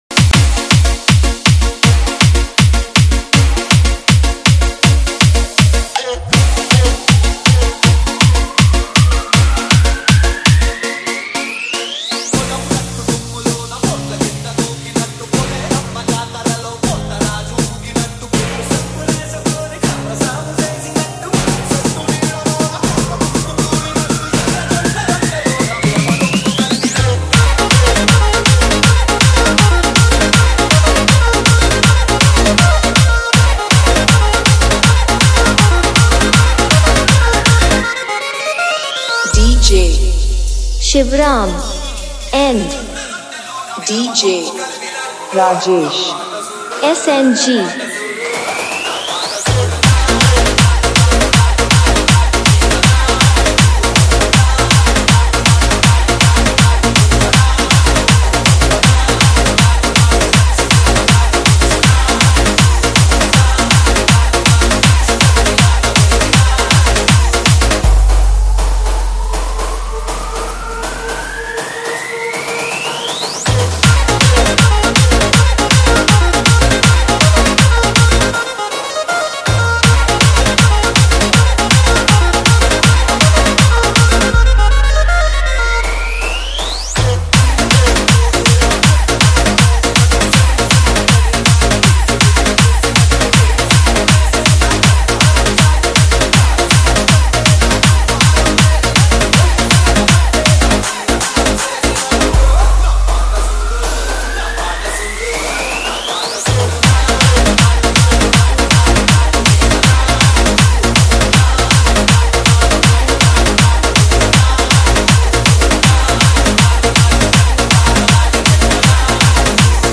Telug Dj Collection 2021 Songs Download